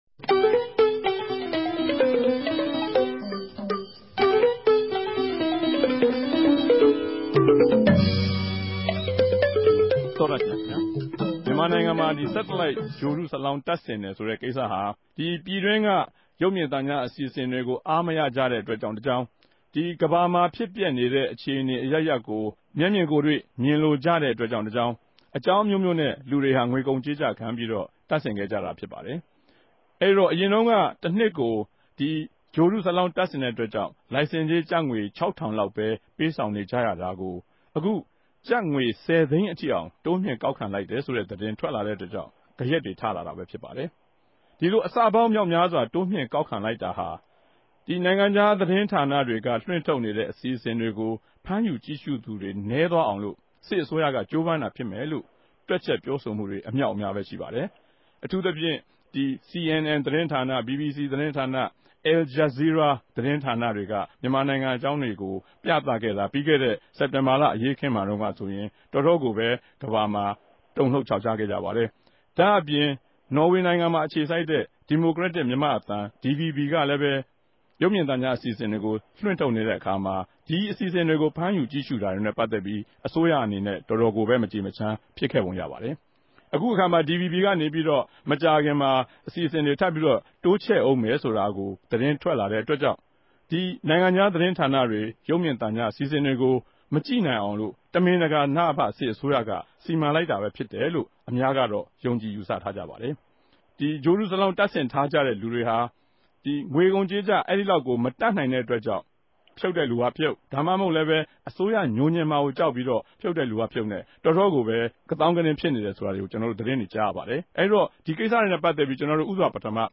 တပတ်အတြင်း သတင်းသုံးသပ်ခဵက် စကားဝိုင်း